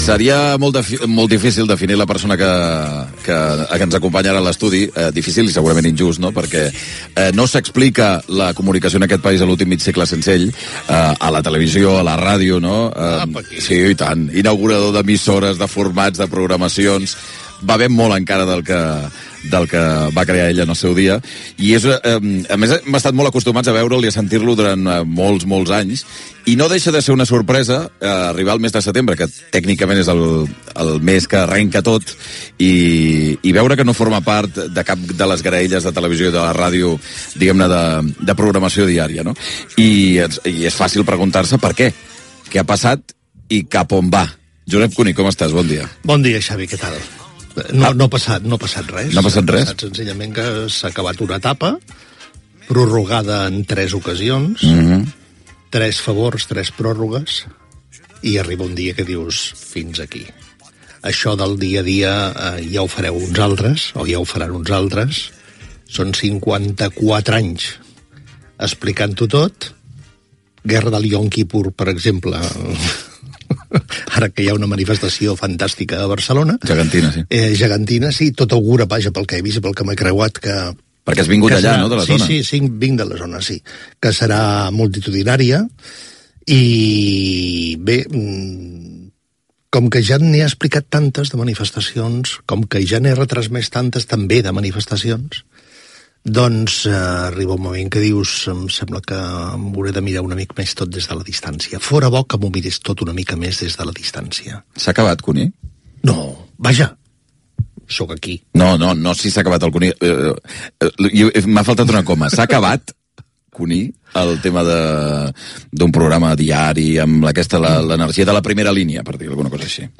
Fragment d'una entrevista al periodista Josep Cuní, després de presentar el seu últim programa matinal a Radio Nacional de España. Comenta aspectes dels seus 54 anys de feina a la ràdio
Entreteniment